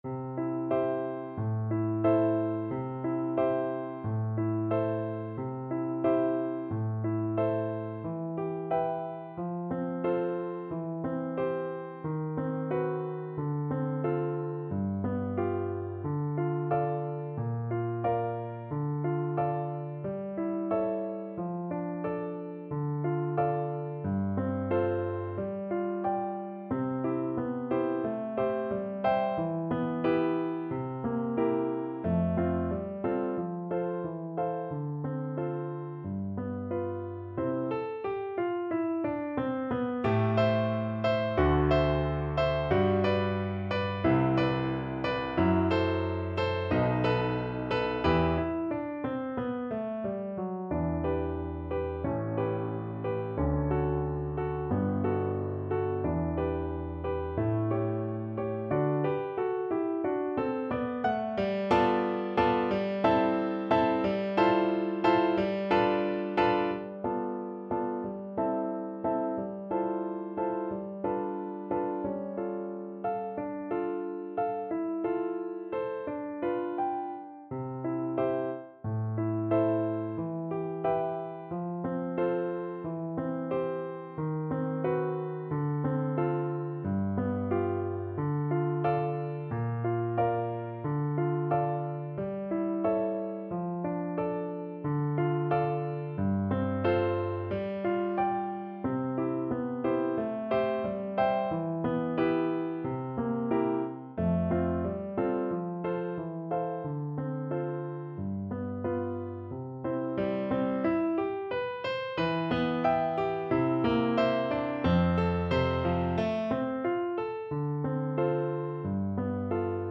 Play (or use space bar on your keyboard) Pause Music Playalong - Piano Accompaniment Playalong Band Accompaniment not yet available reset tempo print settings full screen
C major (Sounding Pitch) (View more C major Music for Flute )
~ = 100 Allegretto con moto =90
2/4 (View more 2/4 Music)
Classical (View more Classical Flute Music)